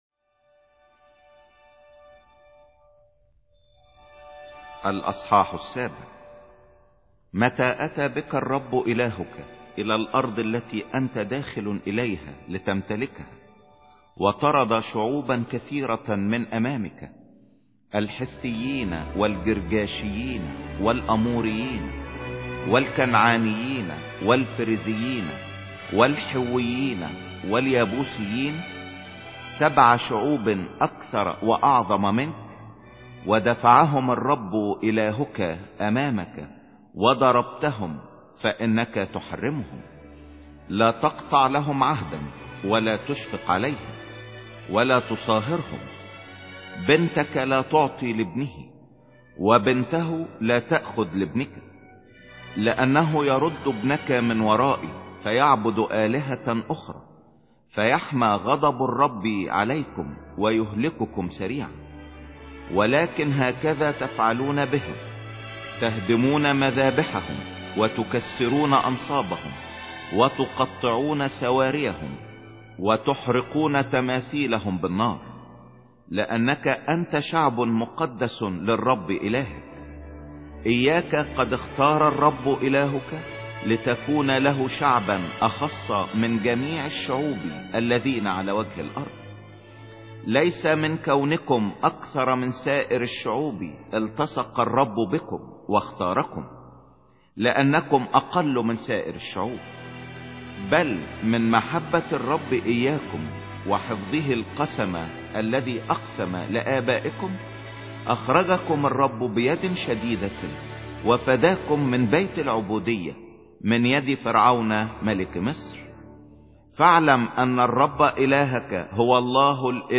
سفر التثنية 07 مسموع